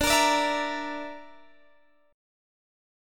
Dsus2#5 Chord (page 2)
Listen to Dsus2#5 strummed